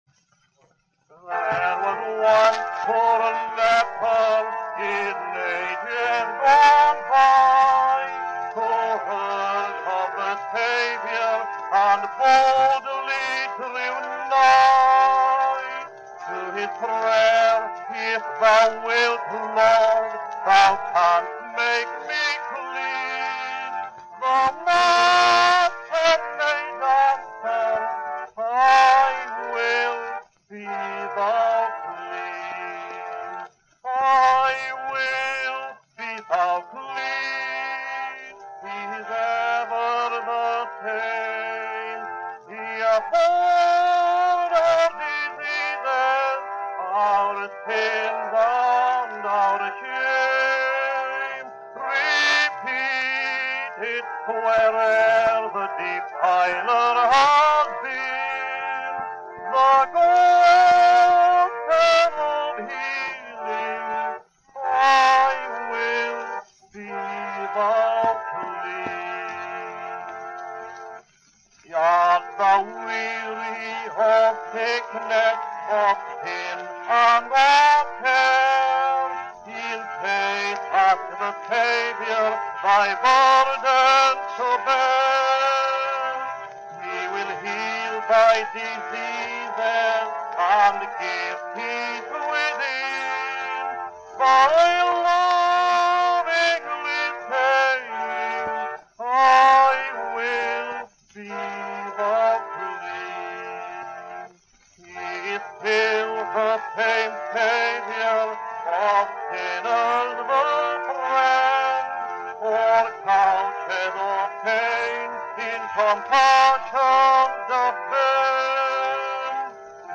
I Will, Be Thou Clean (Tenor Solo)